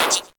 bluePop.ogg